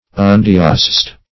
Search Result for " undiocesed" : The Collaborative International Dictionary of English v.0.48: Undiocesed \Un*di"o*cesed\, a. Unprovided with a diocese; having no diocese.